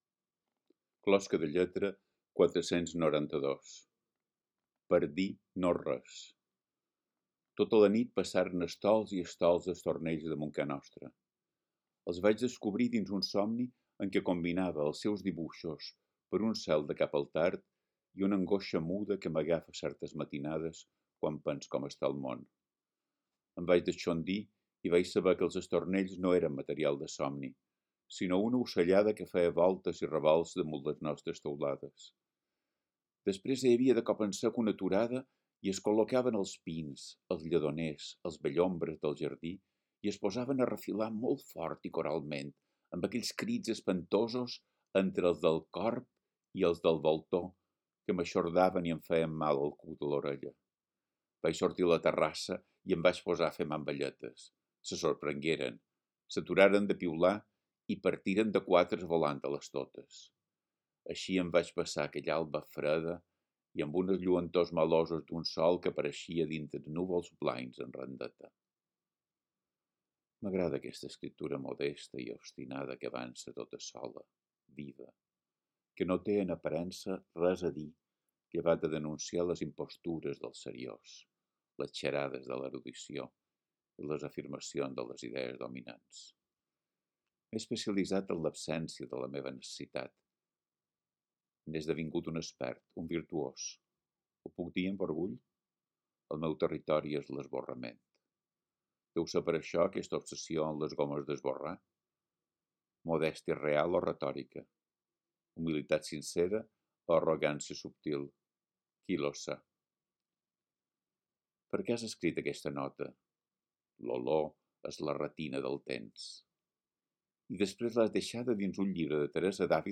Podeu escoltar el text recitat per Biel Mesquida mateix: